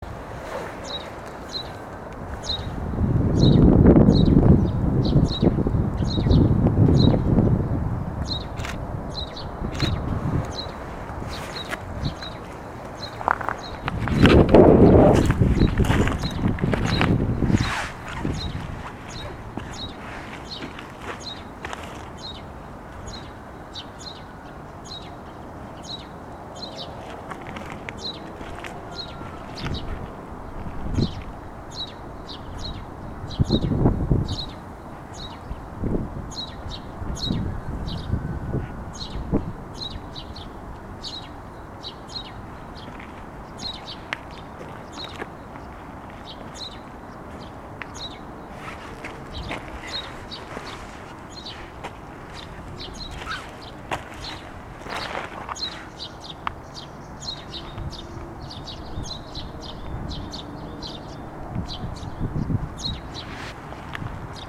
نام فارسی : گنجشک درختی
نام انگلیسی : Eurasian Tree Sparrow